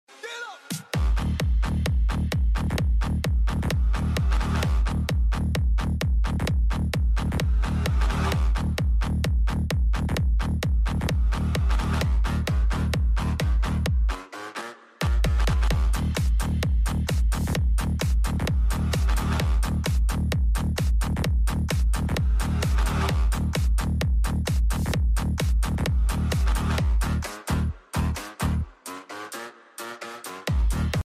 Hyatt Regency Kuantan, Annual Dinner 2024 After Party